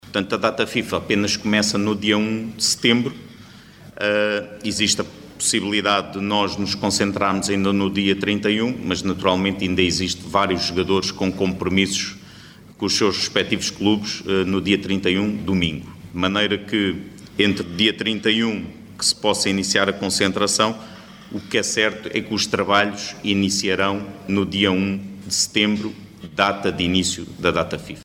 Pedro Gonçalves, selecionador nacional aborda o início do trabalho hoje.